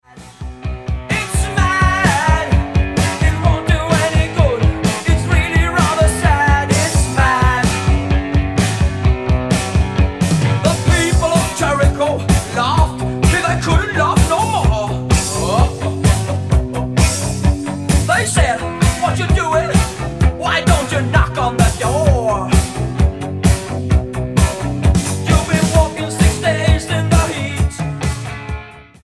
STYLE: Hard Music
almost has a new wave feel